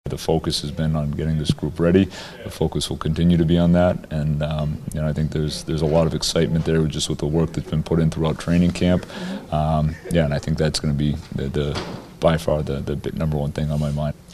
Muse says there will be plenty of emotions in the building tonight, but his focus is on the team he’s putting onto the ice.